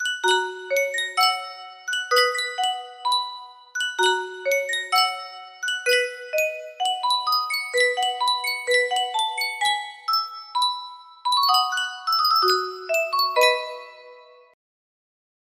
Yunsheng Music Box - His Eye Is on the Sparrow 5962 music box melody
Full range 60